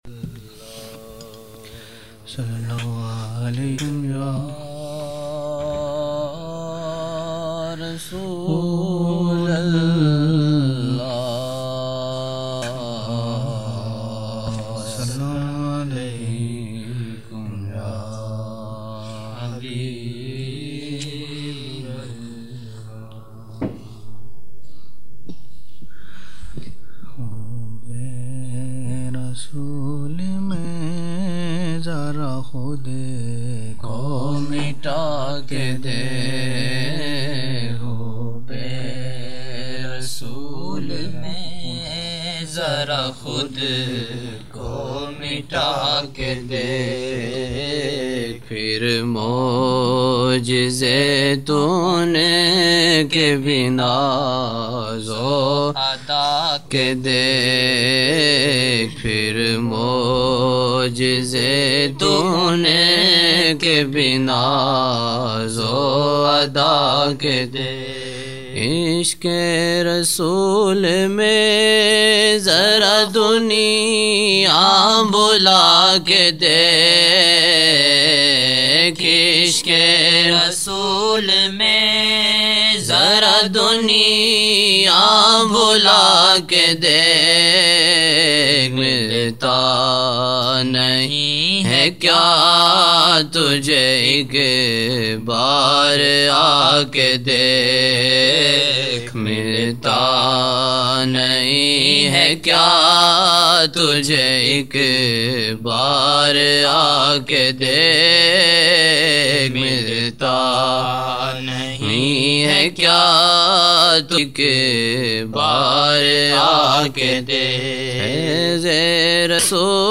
Hub-e-Rasool SAW mein zara khud ko Mita k daikh 11/11/1999 - Maghrib 11 Nov 1999 Old Naat Shareef